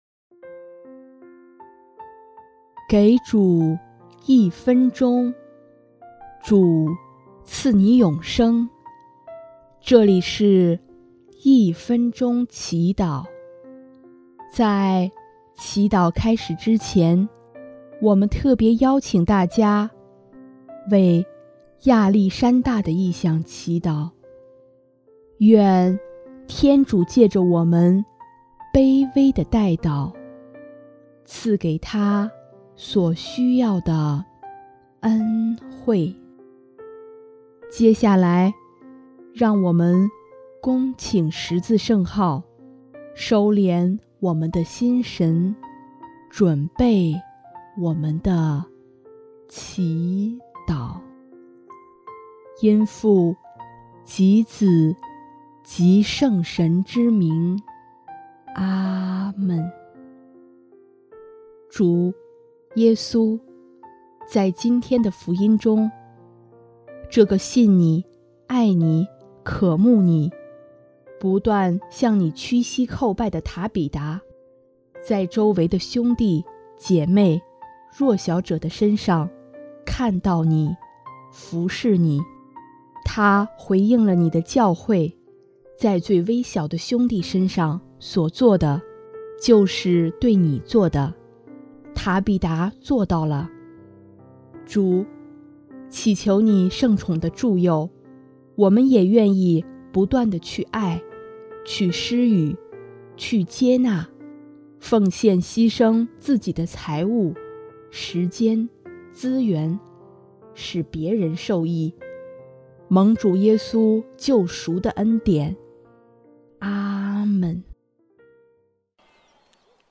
【一分钟祈祷】|4月20日 施舍蒙主祝福
音乐： 主日赞歌《蒙福者》